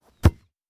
Field Goal Kick Powerful.wav